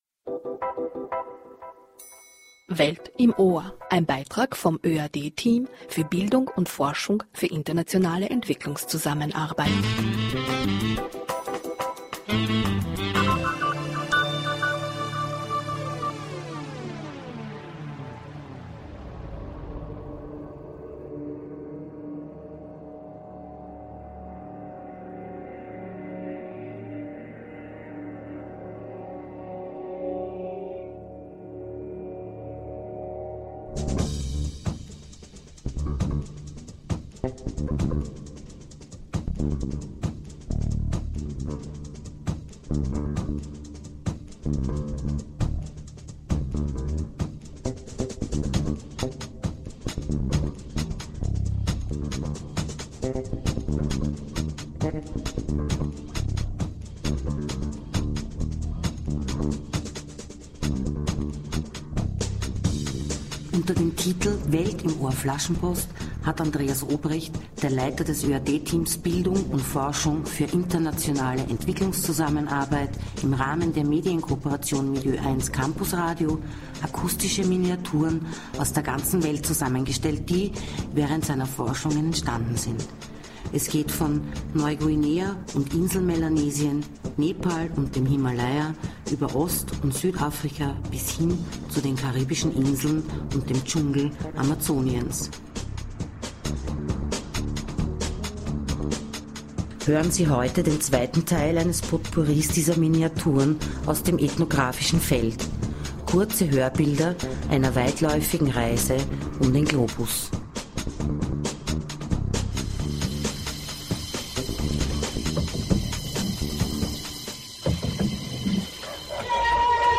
In dem zweiten Teil der akustischen Miniaturen geht es von Neuguinea und Inselmelanesien, über den Himalaya, Ostafrika und Südafrika bis hin zu den karibischen Inseln und dem Dschungel Amazoniens.